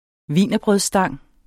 Udtale [ ˈviˀnʌbʁœðsˌsdɑŋˀ ]